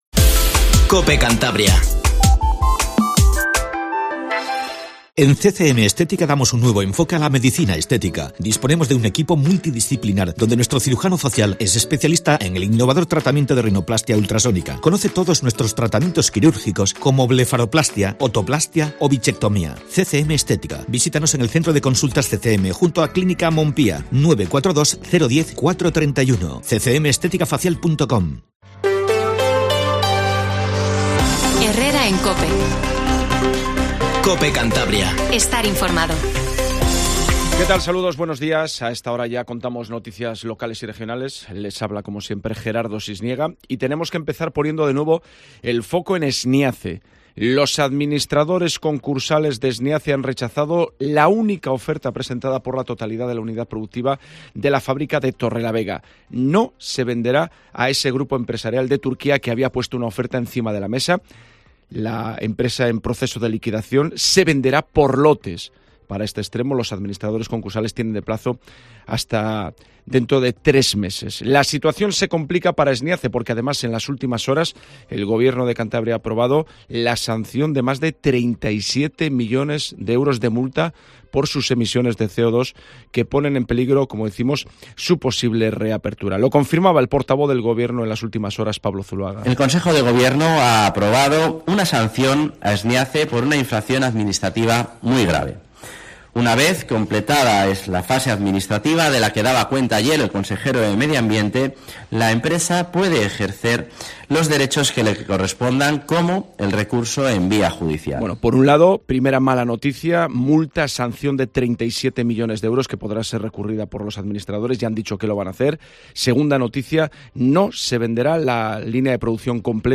informativo Matinal Cope Cantabria